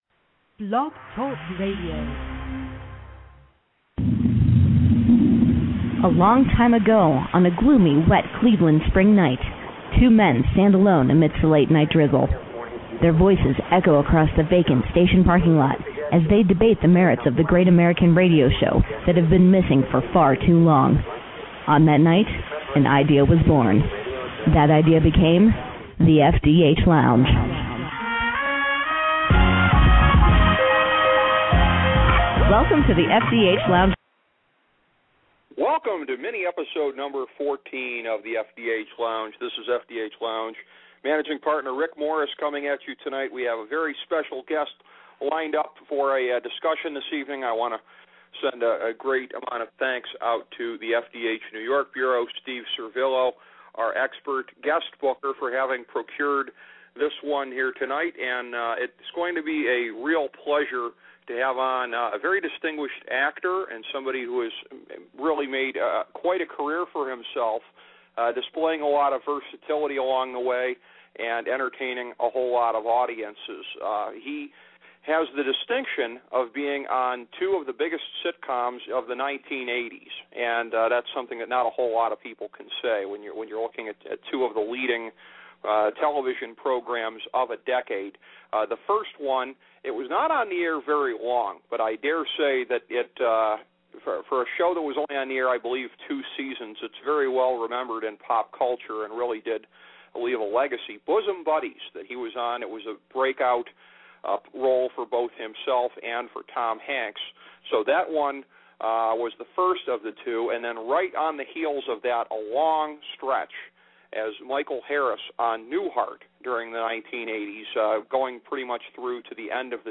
A coversation with actor Peter Scolari